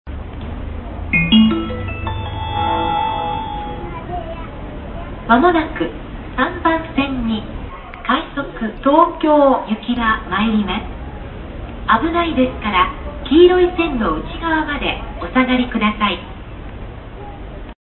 導入当初の放送は一部駅で一部列車の放送で聞けます。